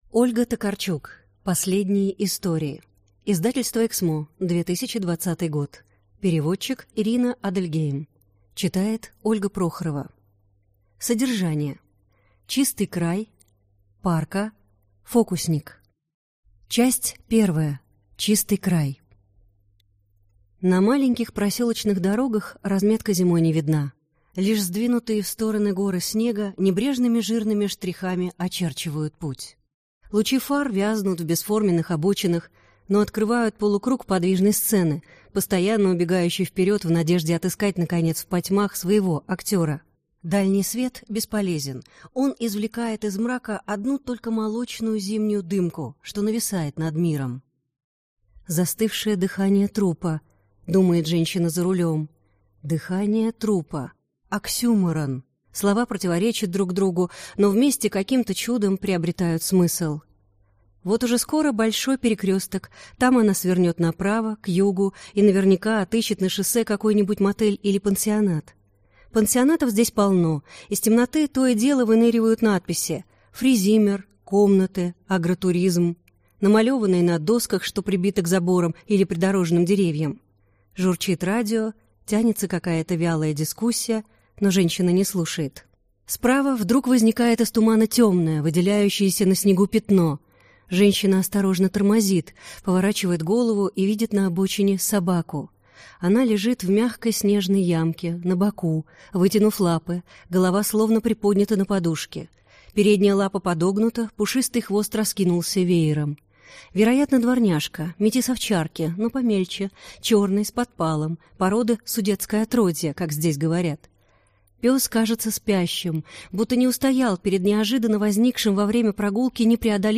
Аудиокнига Последние истории | Библиотека аудиокниг
Прослушать и бесплатно скачать фрагмент аудиокниги